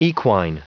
Prononciation du mot equine en anglais (fichier audio)
Prononciation du mot : equine